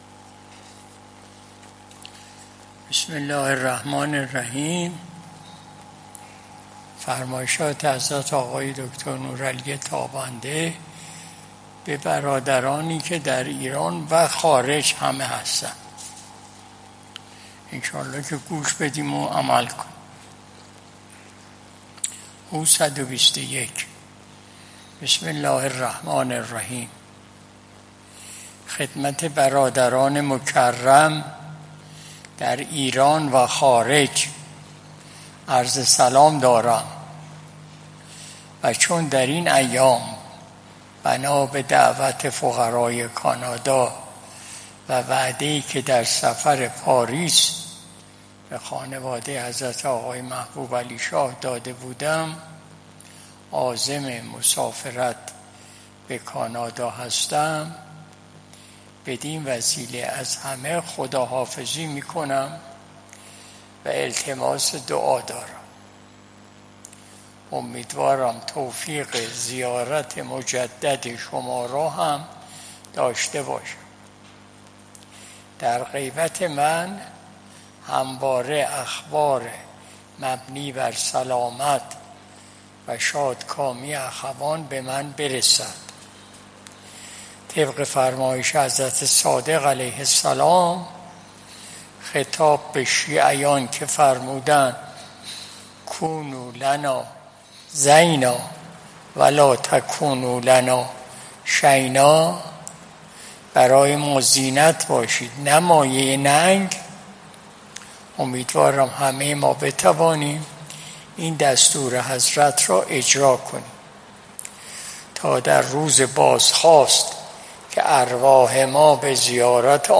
مجلس شب دوشنبه ۱۳ شهریور ماه ۱۴۰۱ شمسی